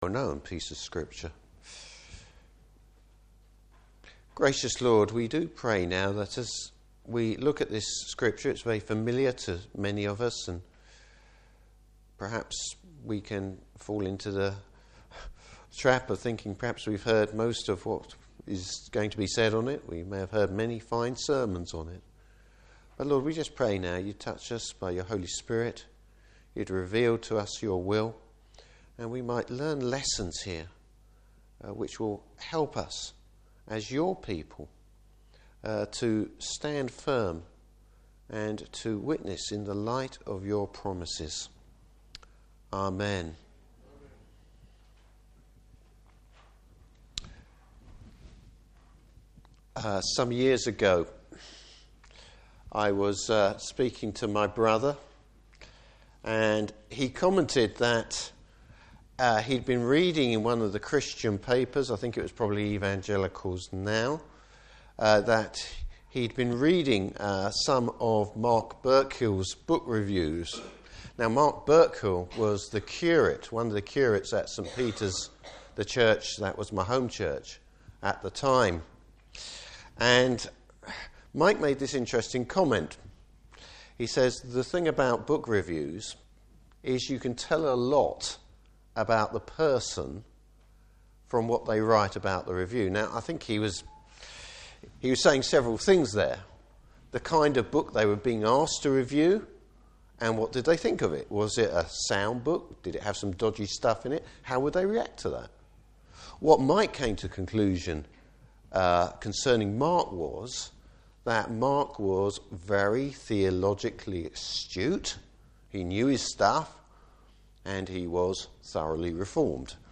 Service Type: Morning Service Bible Text: Luke 1:39-56.